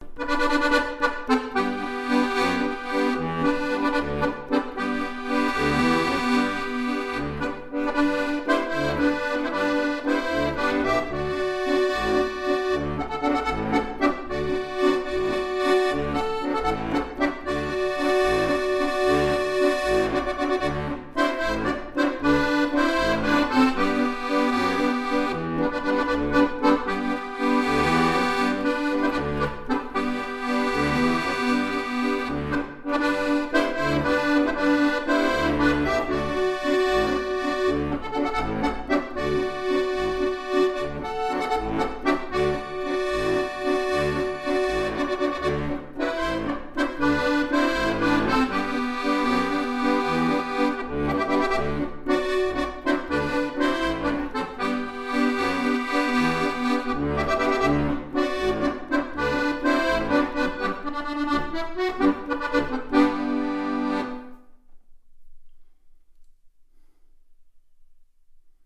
ziach1.mp3